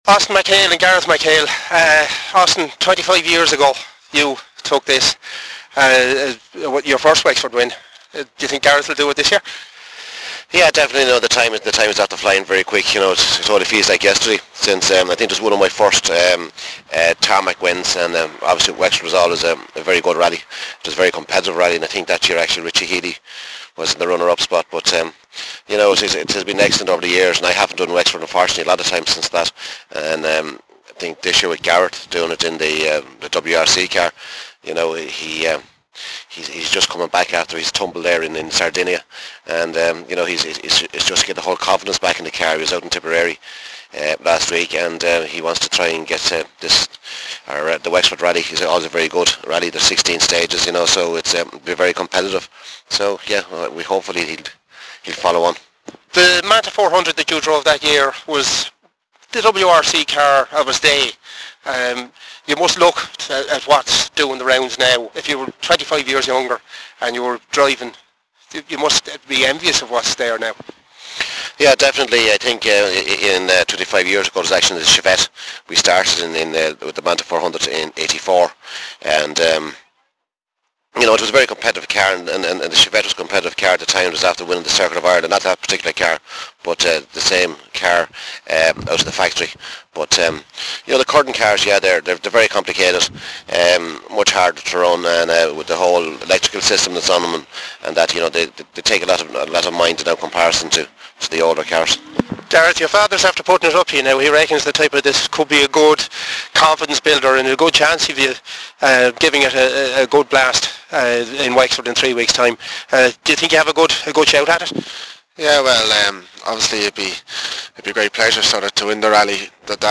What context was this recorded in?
at rally launch